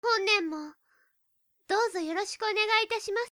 / M｜他分類 / L50 ｜ボイス